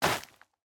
Minecraft Version Minecraft Version latest Latest Release | Latest Snapshot latest / assets / minecraft / sounds / block / netherwart / step5.ogg Compare With Compare With Latest Release | Latest Snapshot
step5.ogg